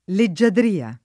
leggiadria [ le JJ adr & a ] s. f.